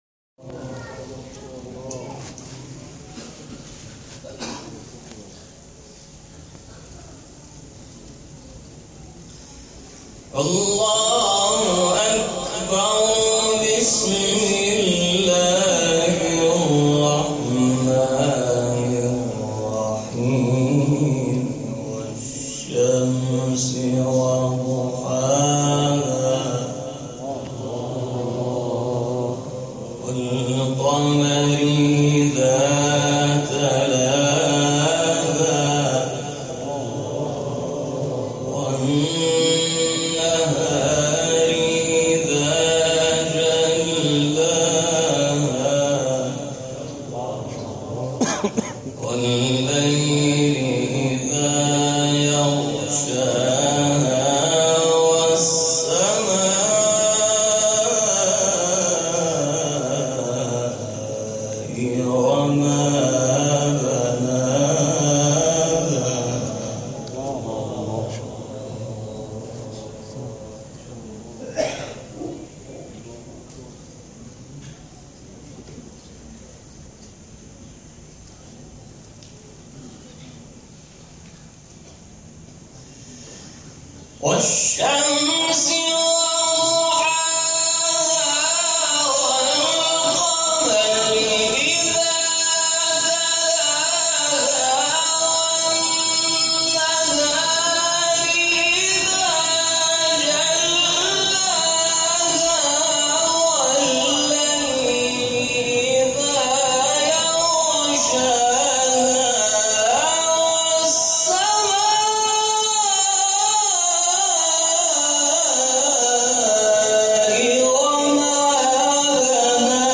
گروه شبکه اجتماعی: مقاطعی از تلاوت قاریان بین‌المللی و ممتاز کشور‌ را می‌شنوید.
سوره مبارکه شمس اجرا شده در مسجد امام موسی‌بن جعفر(ع) شهرک رازی